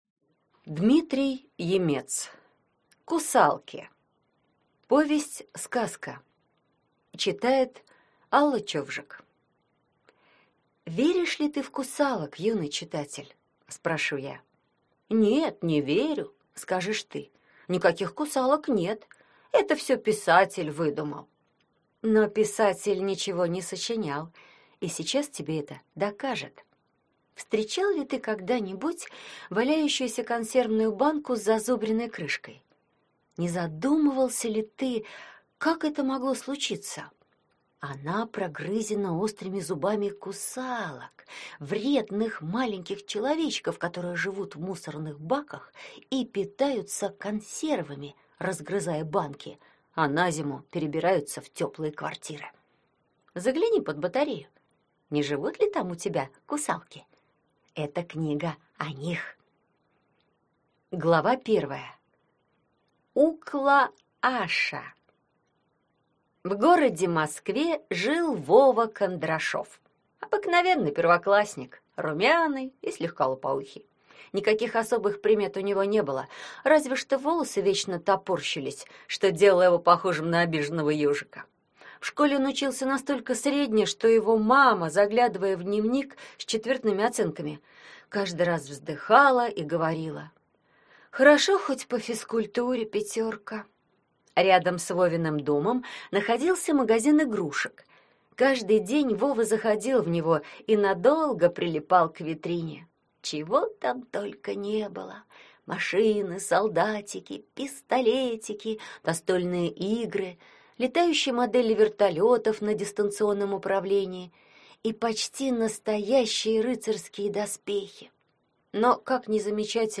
Аудиокнига Кусалки | Библиотека аудиокниг